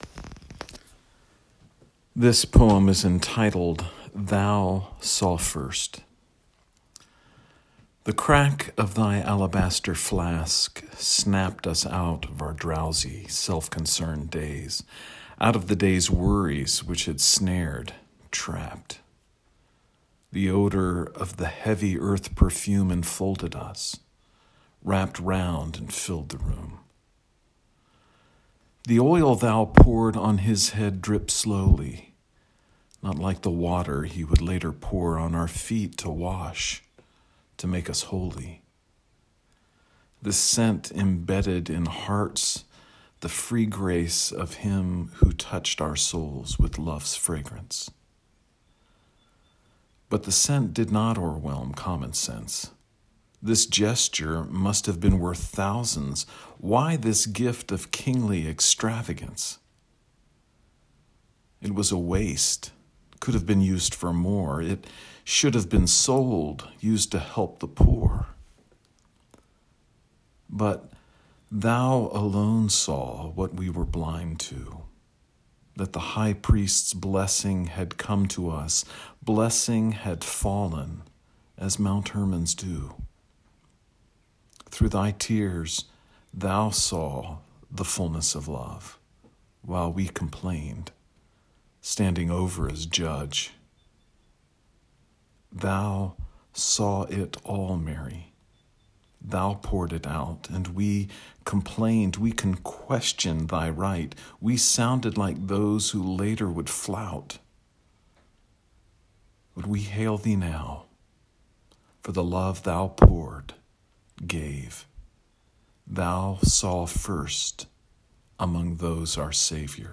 You may listen to me read the poem via the player below.